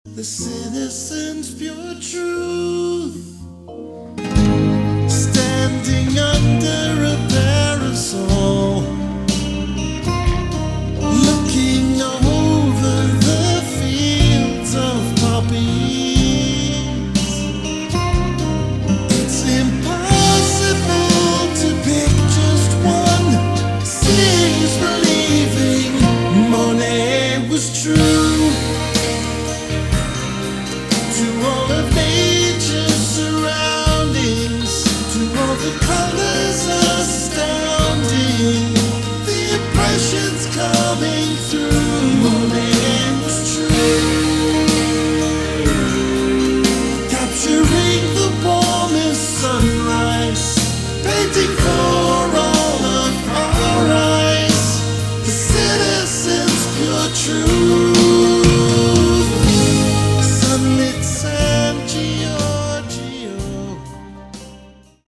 lead and backing vocals, guitars, drums